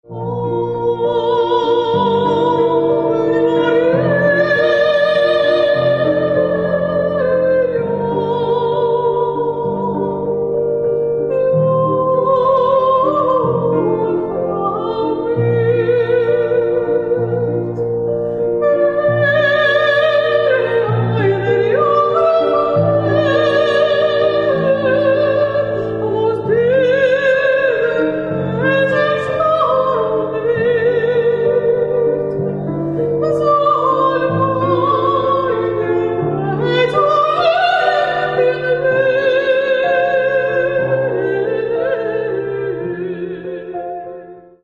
К сожалению, качество записи некоторых песен не безупречно.